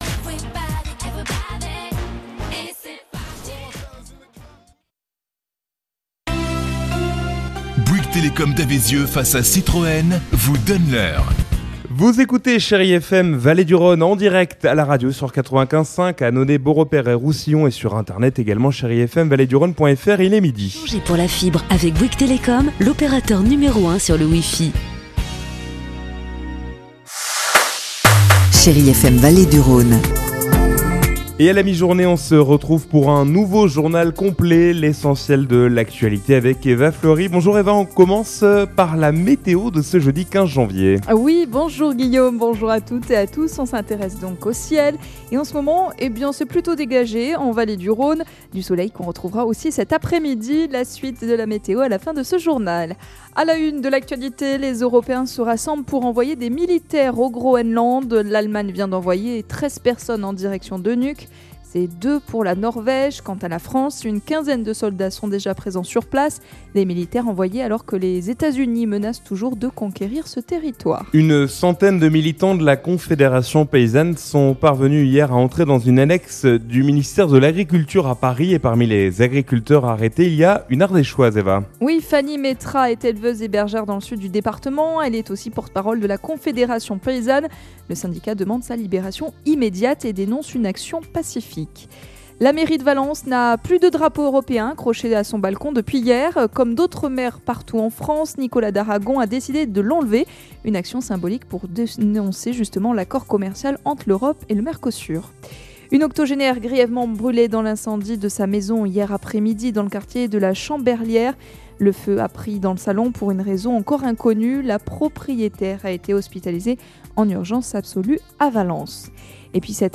Jeudi 15 janvier : Le journal de 12h